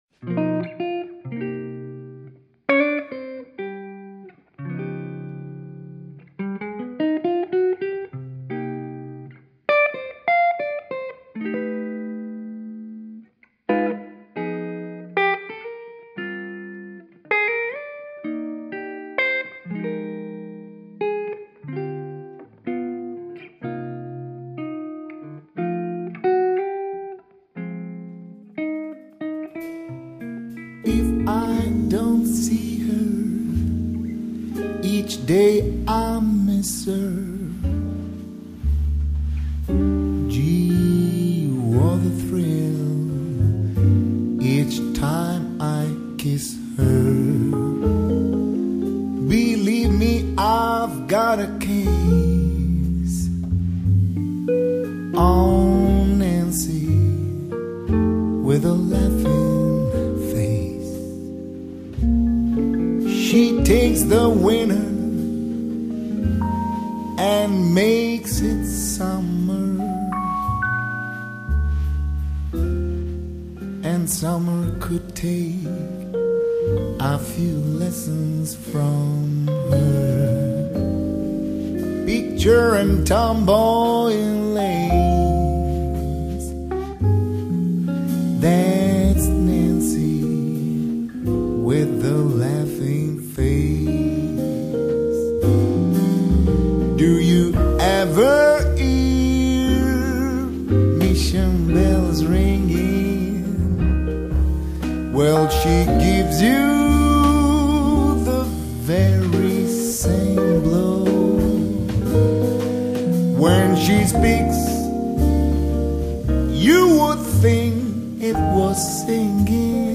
类别： 爵士
主奏乐器：钢琴